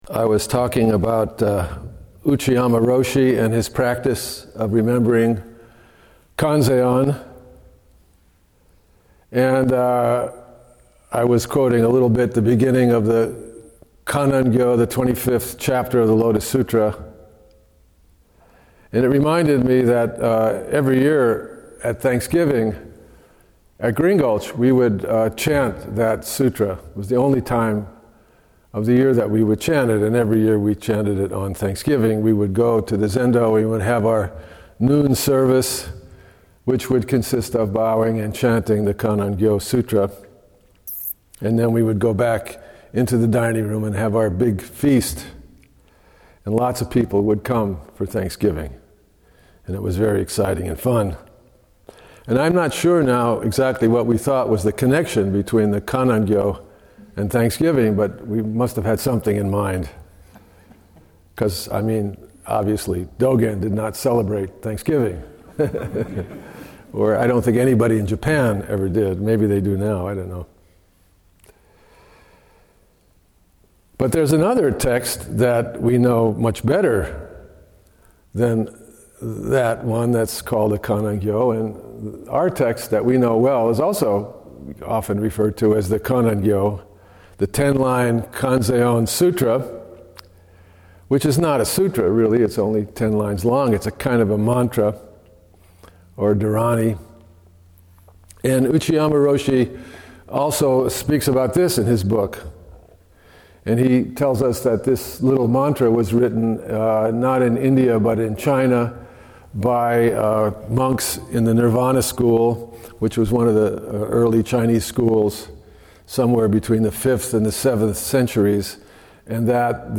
En Mei Jukku Kannongyo – Samish Island Sesshin 2025 – Talk 3 – 6/17/25